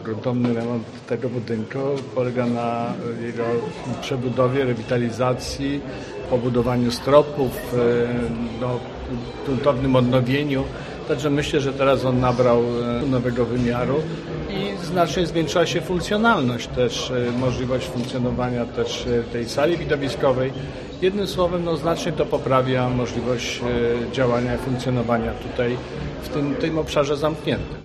O realizacji tego zadania mówił przed dzisiejszym uroczystym otwarciem tej placówki, Marszałek Województwa Mazowieckiego, Adam Struzik: